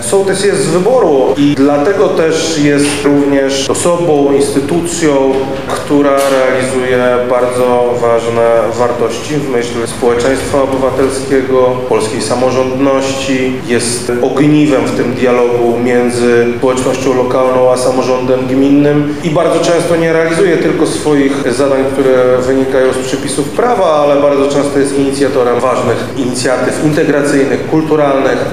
Dzisiaj (17.03) w Lubelskim Urzędzie Wojewódzkim odbyły się obchody tego dnia połączone z finałem etapu wojewódzkiego Konkursu „Fundusz Sołecki – Najlepsza Inicjatywa”.
To jest osoba, która jest najlepiej rozeznana w kwestii lokalnych potrzeb – mówi Krzysztof Komorski, Wojewoda Lubelski.
KrzysztofKomorskioSoltysach.mp3